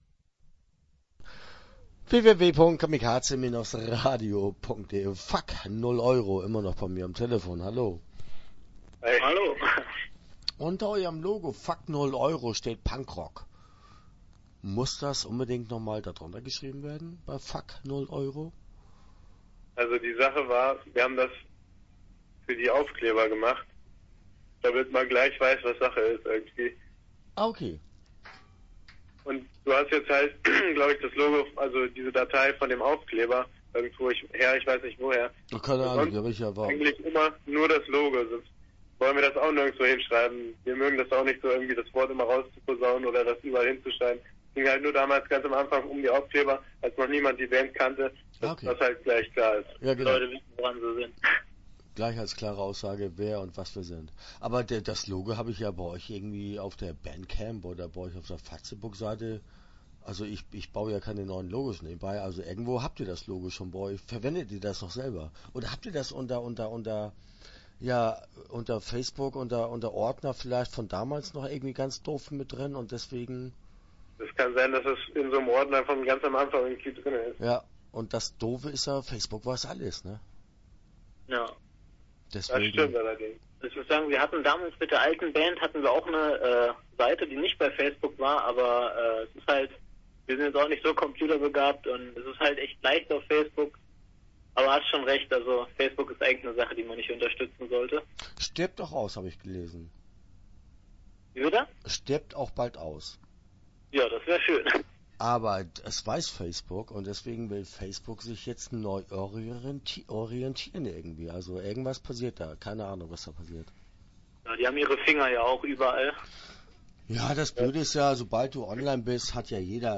Fuck0€ - Interview Teil 1 (12:05)